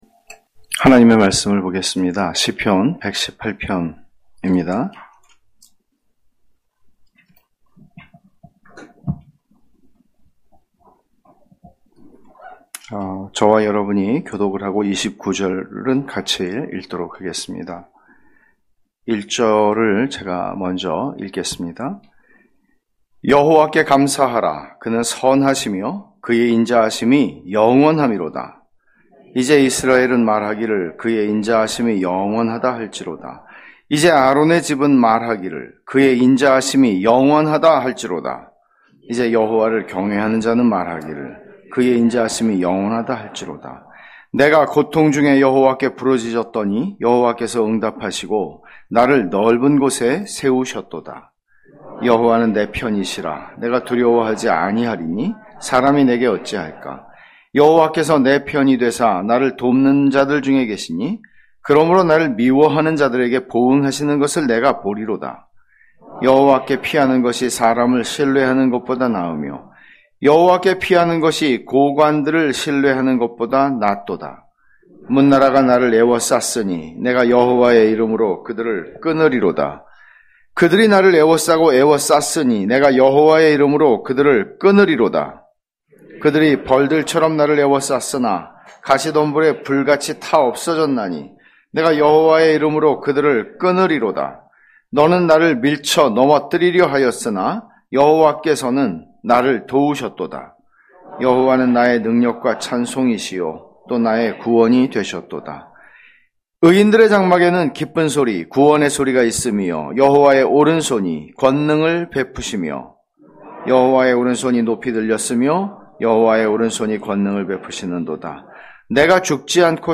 [시편강해](118) 하나님께 감사하라(시 118:1-29)